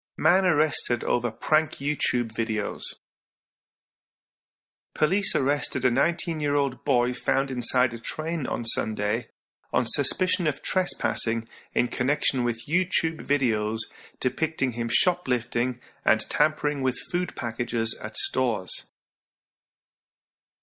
（区切りなしのナレーションです。）